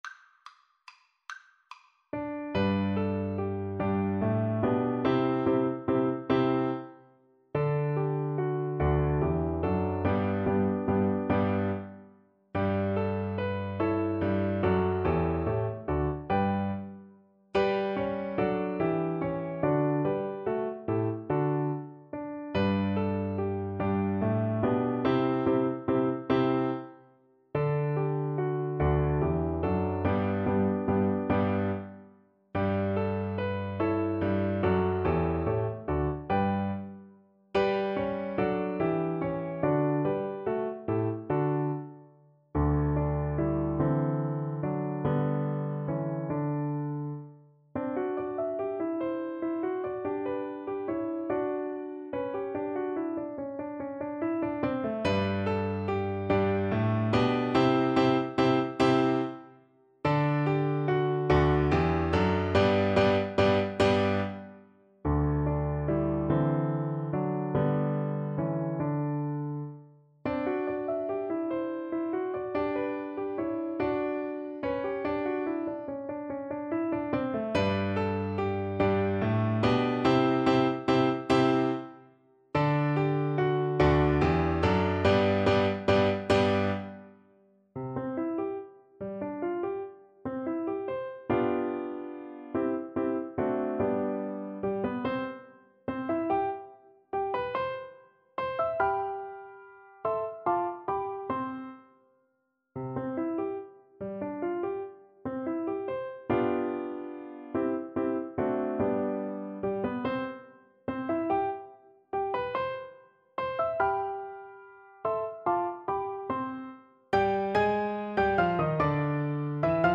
G major (Sounding Pitch) (View more G major Music for Viola )
Moderato =c.144
3/4 (View more 3/4 Music)
minuet-woo-82_VLA_kar1.mp3